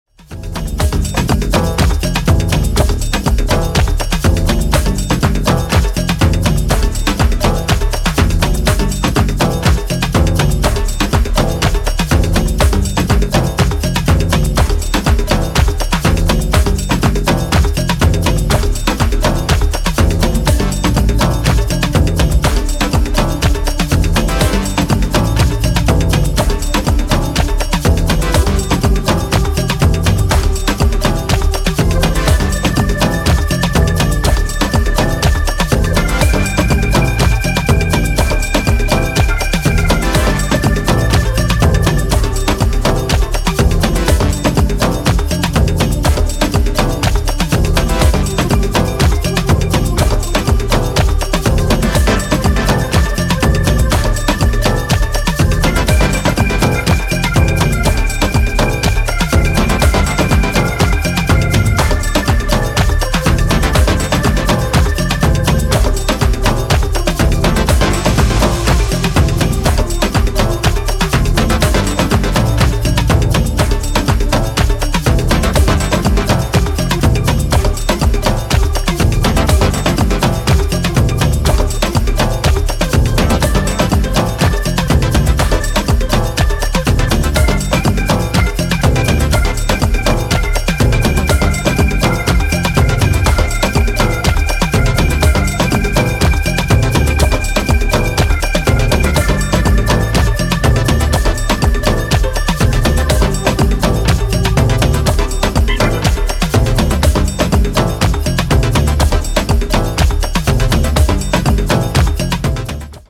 is a fierce percussive and pulsating jam